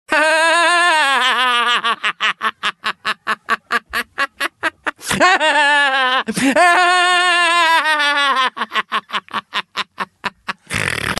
Bræge latter ringetone
braege_ring.mp3